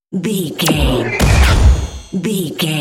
Sci fi shot whoosh to hit
Sound Effects
Atonal
dark
futuristic
intense
woosh to hit